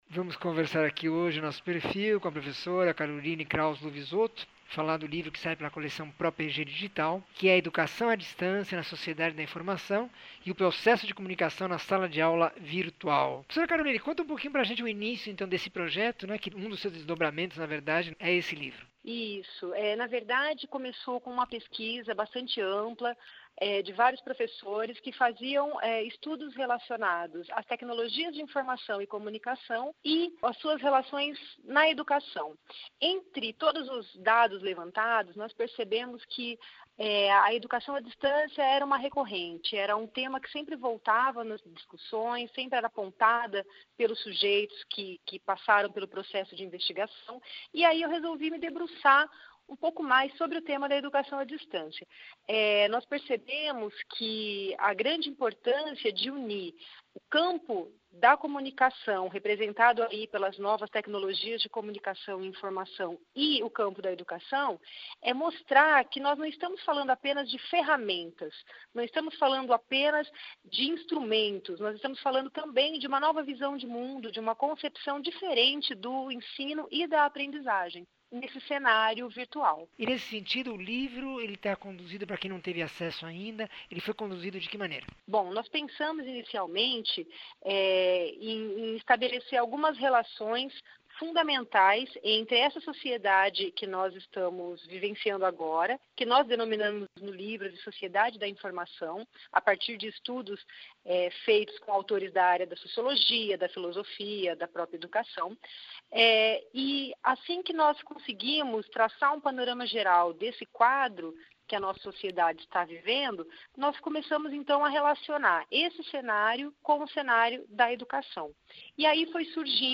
entrevista 2336
Entrevista